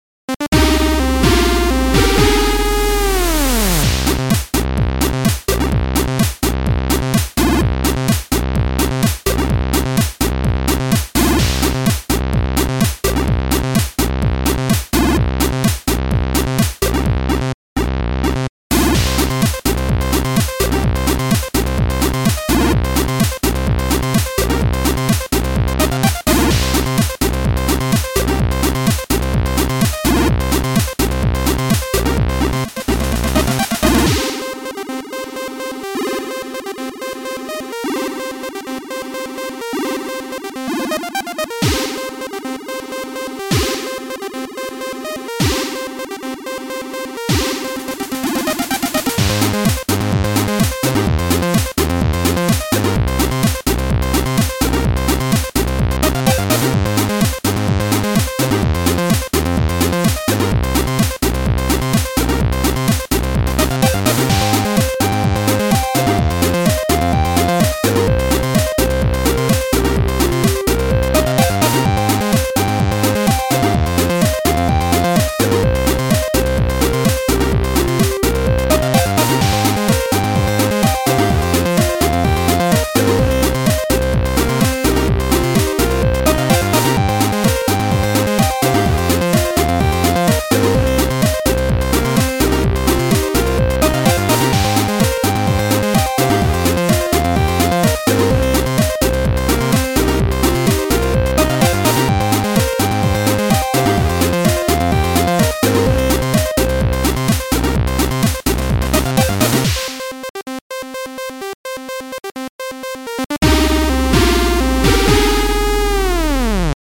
It aggressively rolls off higher frequencies, and then feeds the signal into our custom BBD modeling. The result is dark and rich.
Analog modeled bucket brigade (BBD) reverb
Variable stereo width, even for mono sources
Dark ambience from a lost era of technology